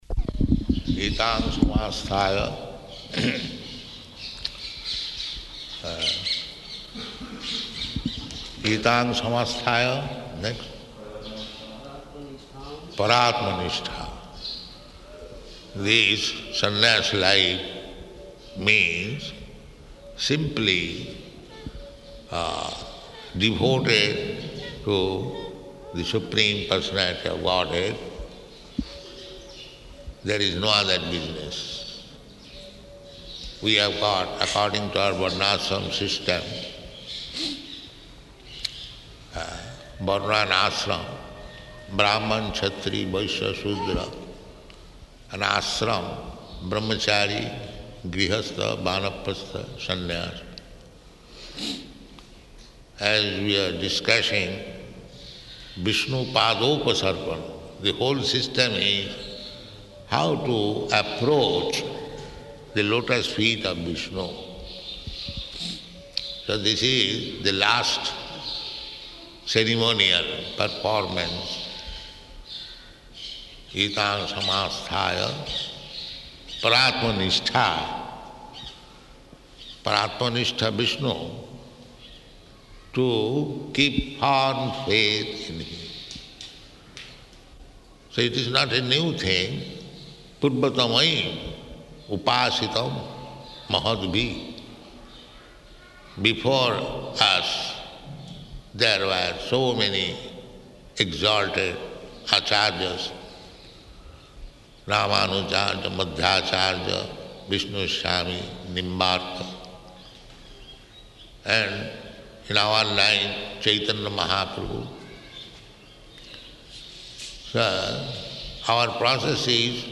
Sannyāsa Initiation Lecture
Sannyāsa Initiation Lecture --:-- --:-- Type: Lectures and Addresses Dated: December 6th 1975 Location: Vṛndāvana Audio file: 751206LE.VRN.mp3 Prabhupāda: Etāṁ sa āsthāya.